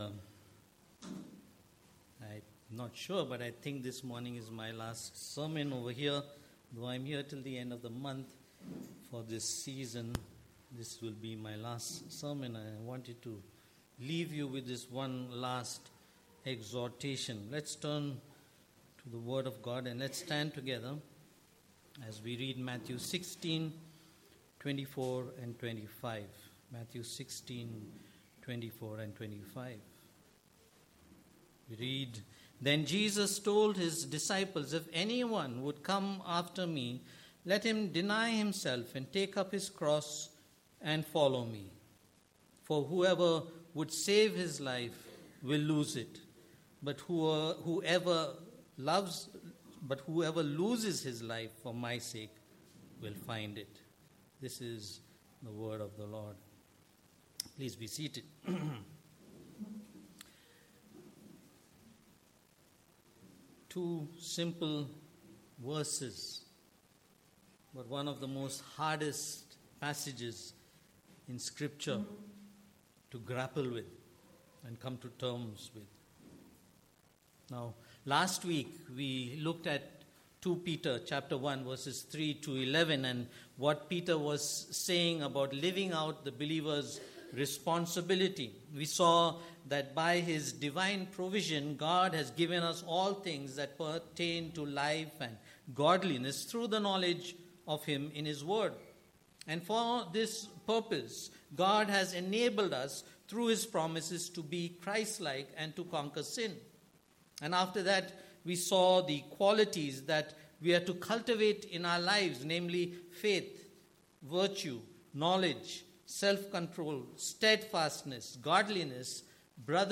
Passage: Matt 16:24-25 Service Type: Sunday Morning